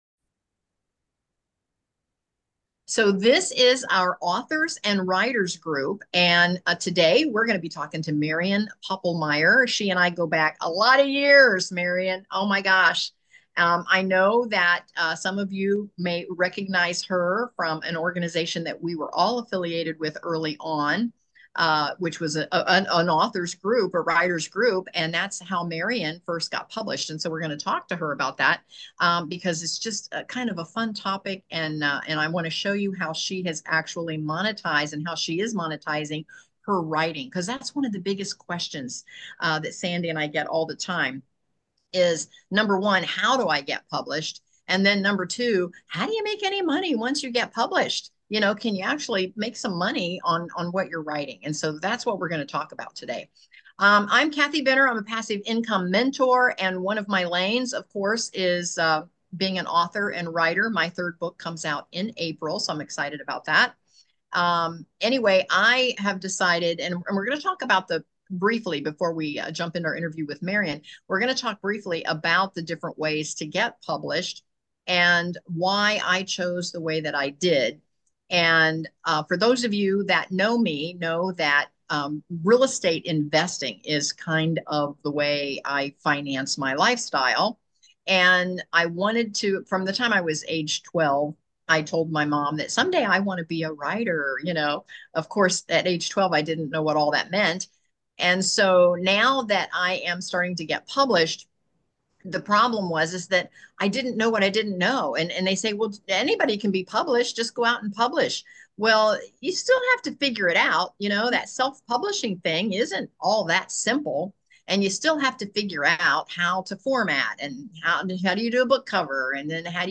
Authors and Writers